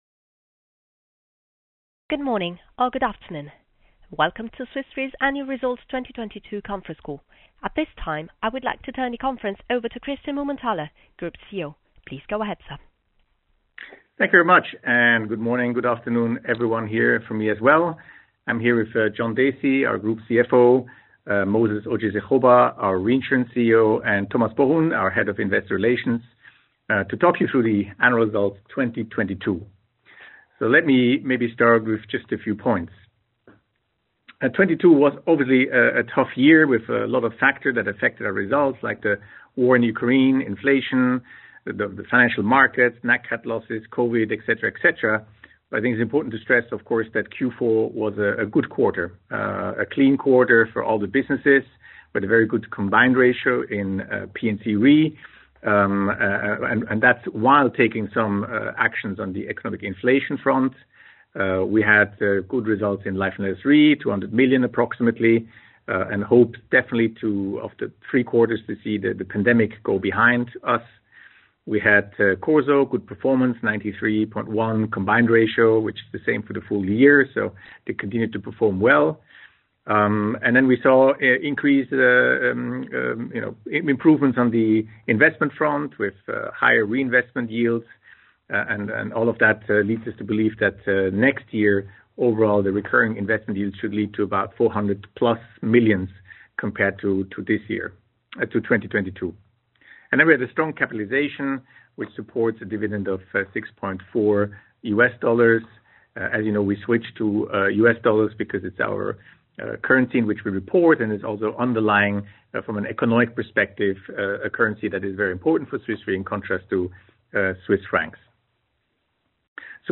fy-2022-call-recording.mp3